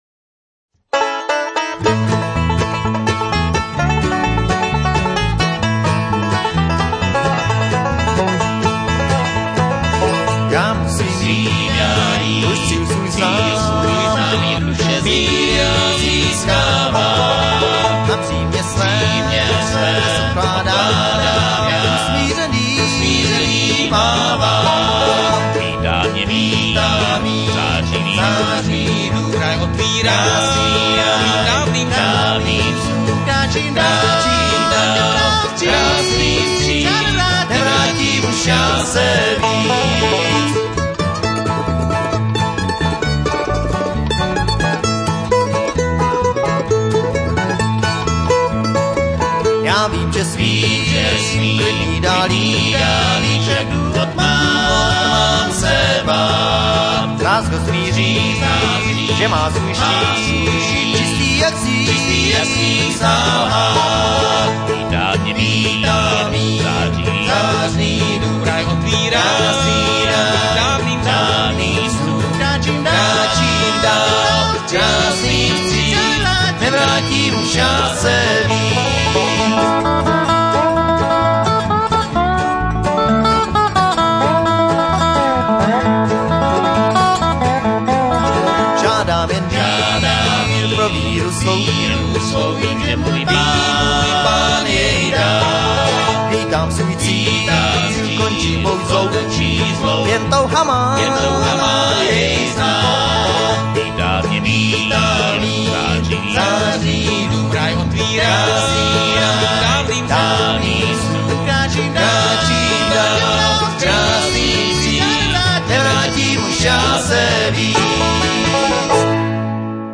guitar, vocal
banjo, vocal
dobro, vocal
mandolin, vocal